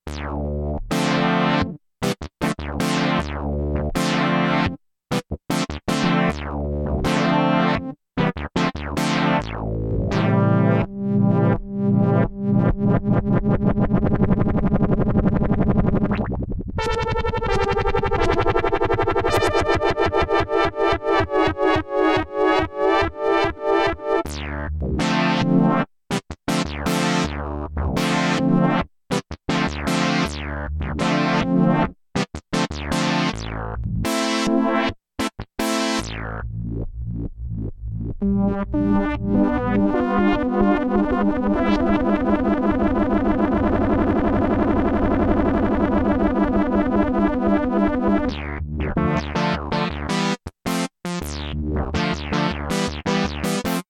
I’ve been on a “straight up synth” kick with PX.
It just detunes every key in a static way that gives just a touch of nice wonk.